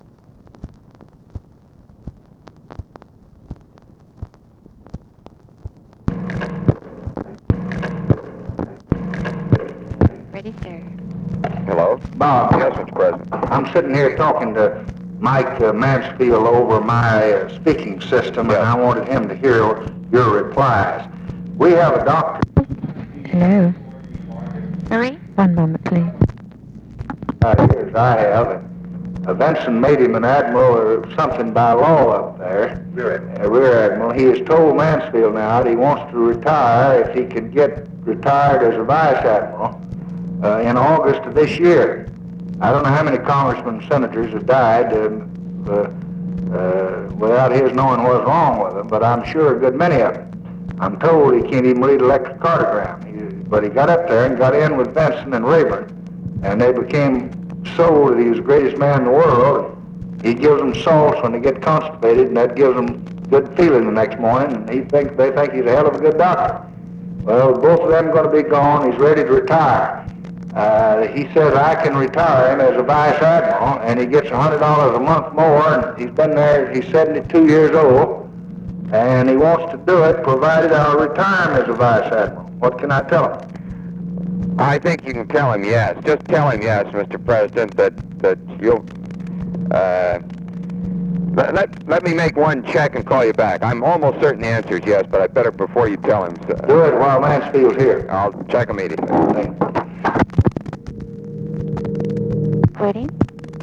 Conversation with ROBERT MCNAMARA, OFFICE SECRETARY and OFFICE CONVERSATION, July 13, 1964
Secret White House Tapes